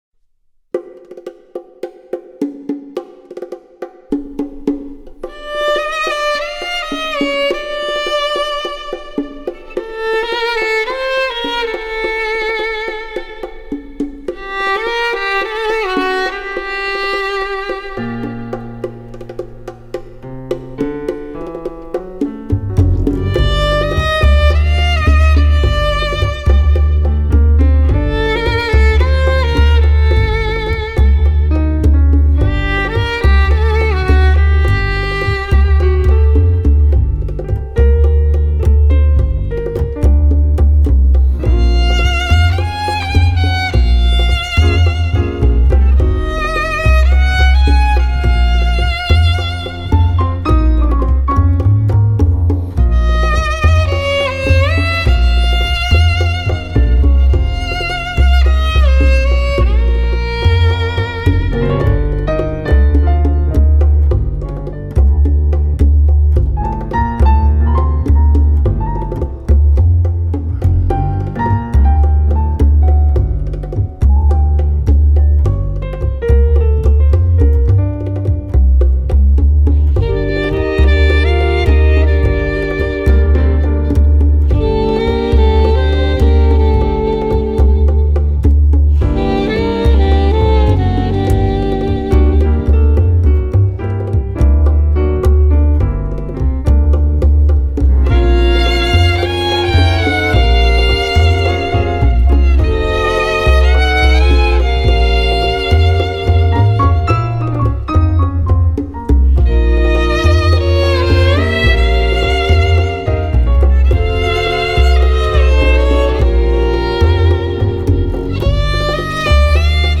风格流派: O.S.T